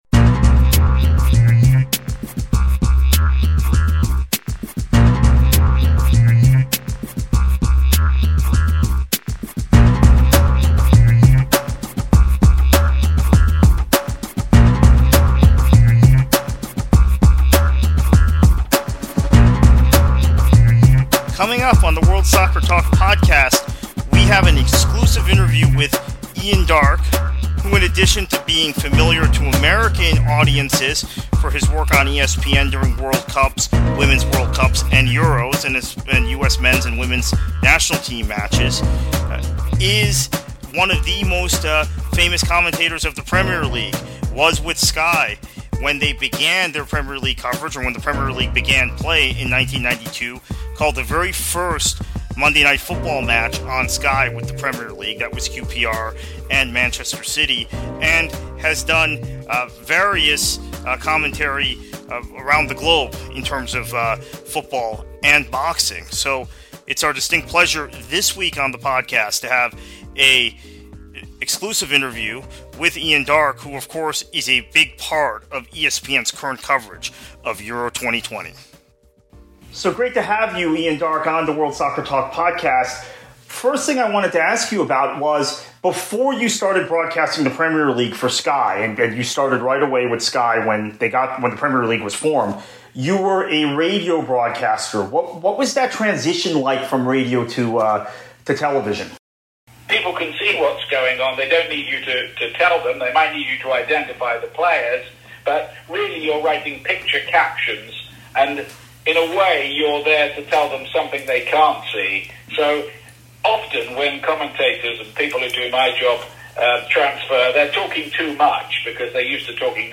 Ian Darke interview: World Soccer Talk Podcast